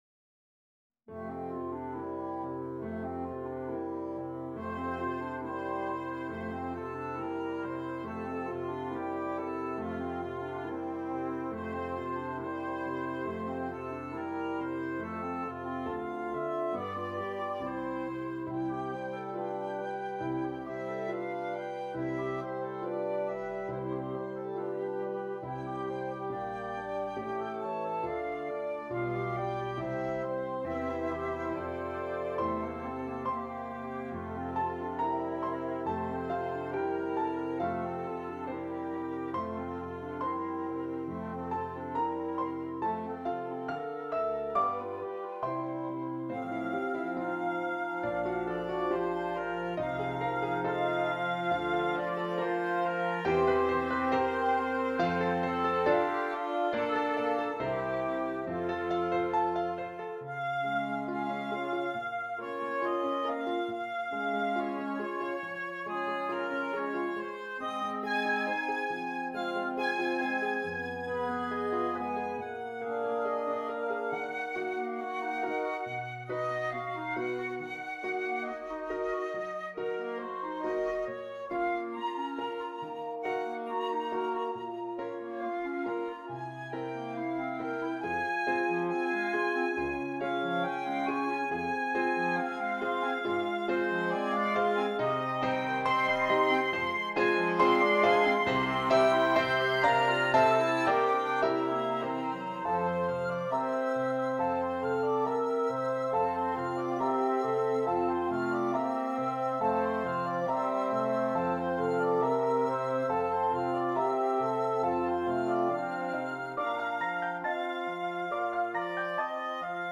Woodwind Quintet and Piano